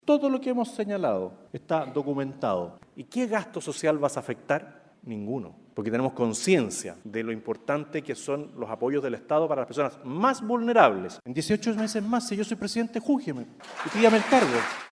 Sin embargo, Kast ahondó en el tema durante su participación en el seminario presidencial de Clapes UC.